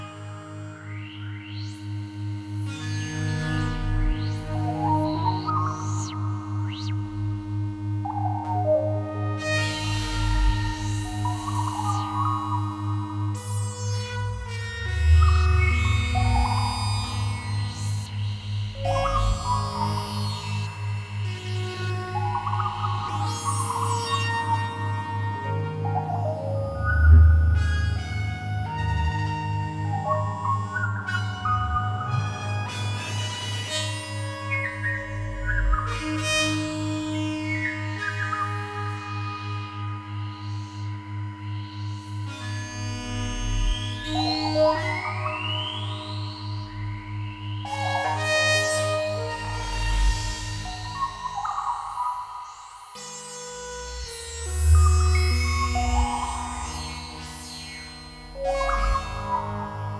Electro Electronix Experimental